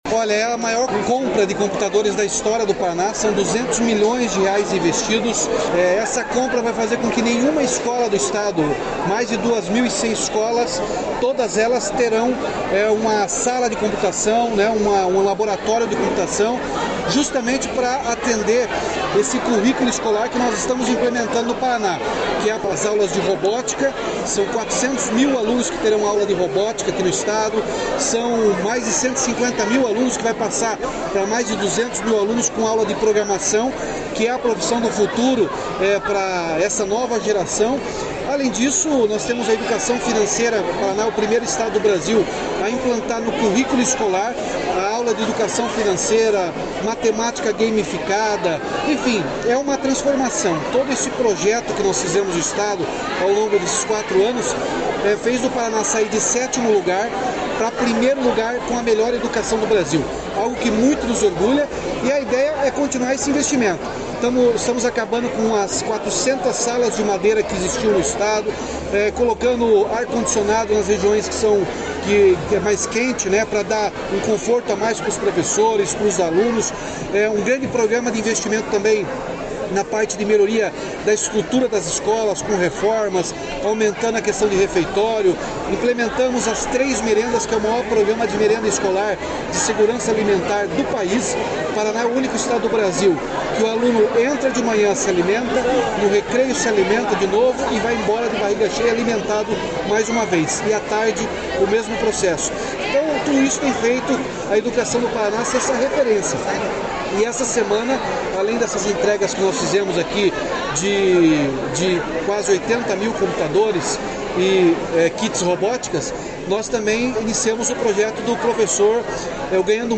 Sonora do governador Ratinho Junior sobre a entrega de 77 mil novos equipamentos de informática para a rede de ensino do Paraná